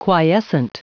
Prononciation du mot quiescent en anglais (fichier audio)
Prononciation du mot : quiescent